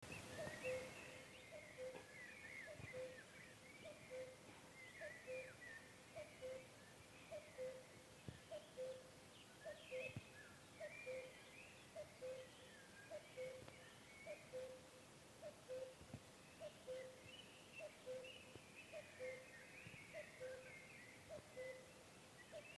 Kuckuck Gesang
• Nur das Männchen ruft das typische „Kuckuck“, meist im Frühling – dieser Ruf ist in Europa ein bekanntes Zeichen für den Frühlingsbeginn.
Kuckuck-Gesang-Voegel-in-Europa.mp3